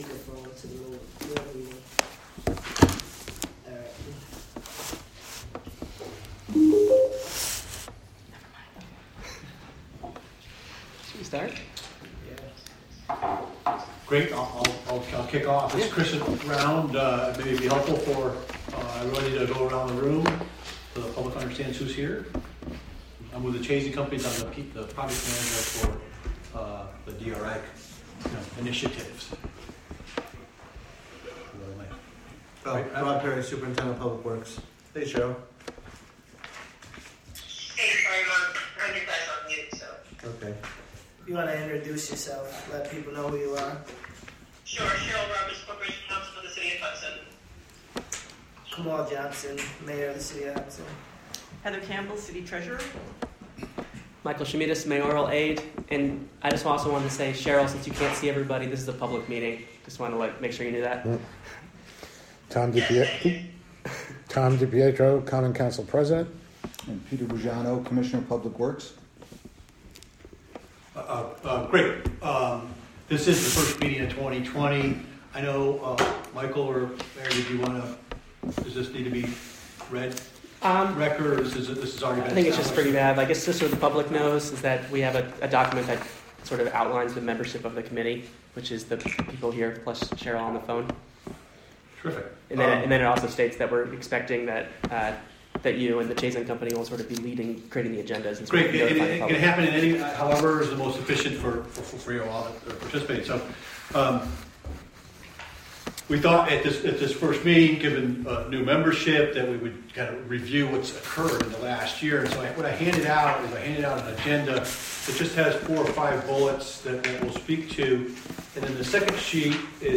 Meeting to be held at City Hall, 520 Warren Street...
Recorded from a live webstream created by the City of Hudson through the Wave Farm Radio app and WGXC.